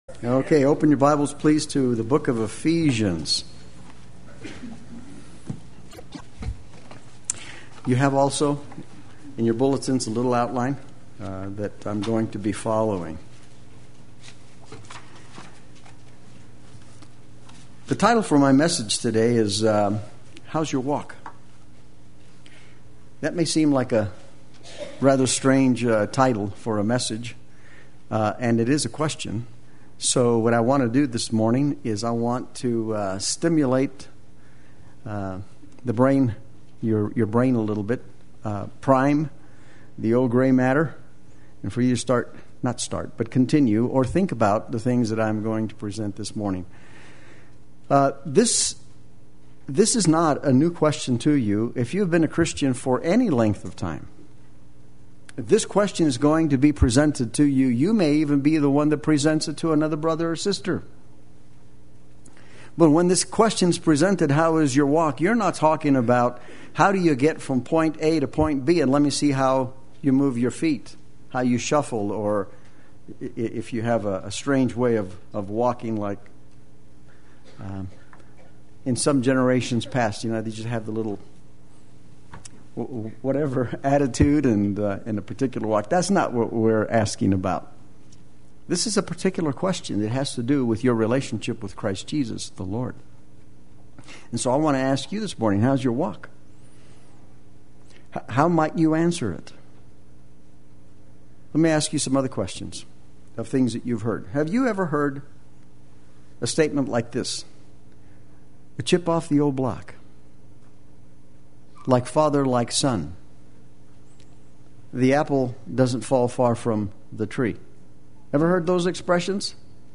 Play Sermon Get HCF Teaching Automatically.
“How’s Your Walk” Sunday Worship